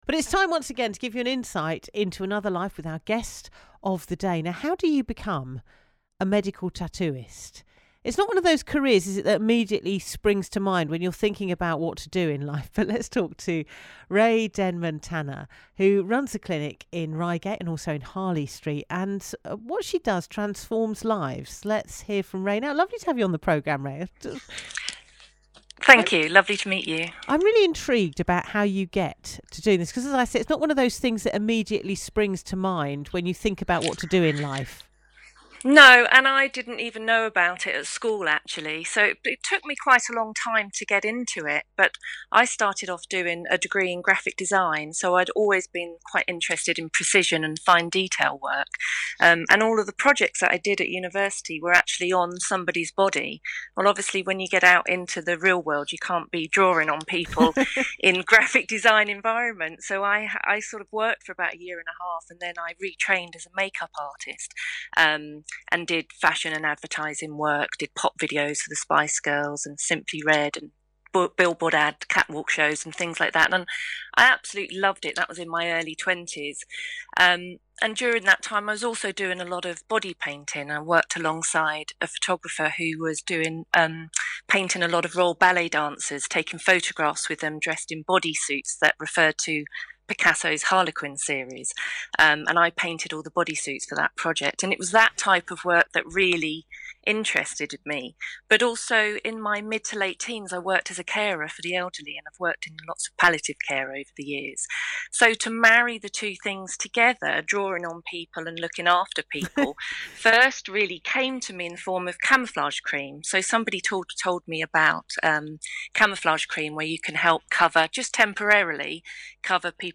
Interview for BBC Radio Surrey